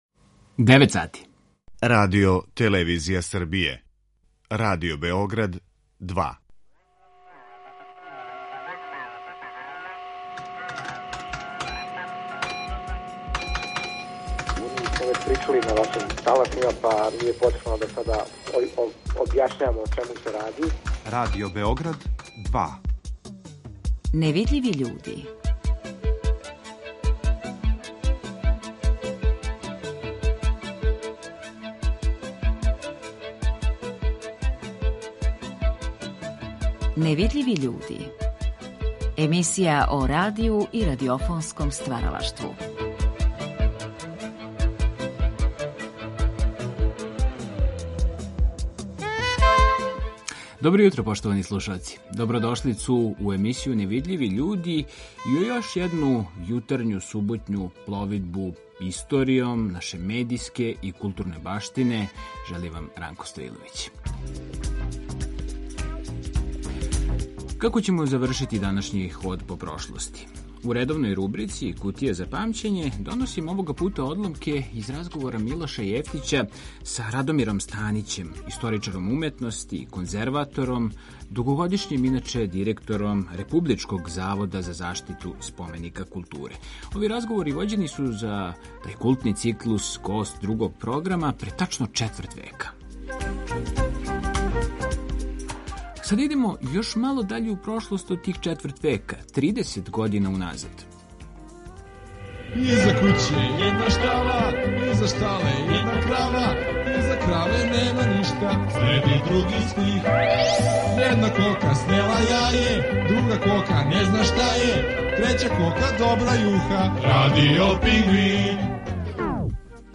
Емисија о радију и радиофонском стваралаштву
Осим успомена и прича, доносе нам и аутентичан, архивски звук од пре тридесет година.